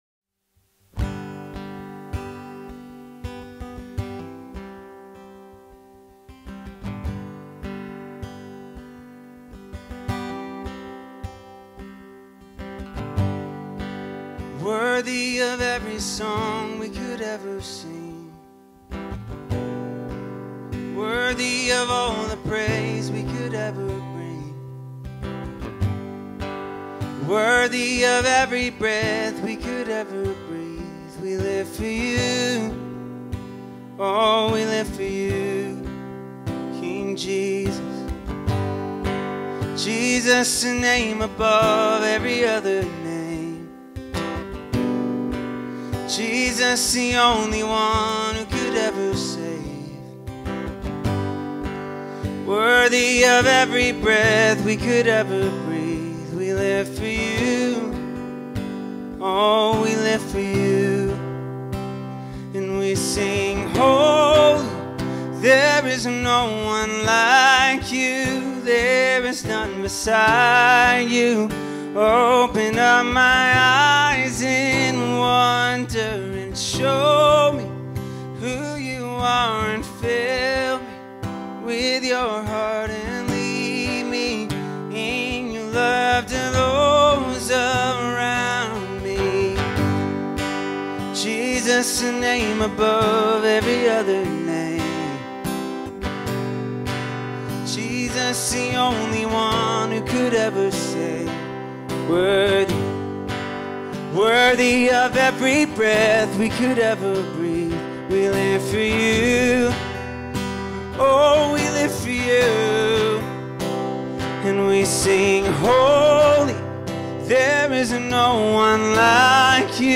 3. Sunday Worship – Second Song: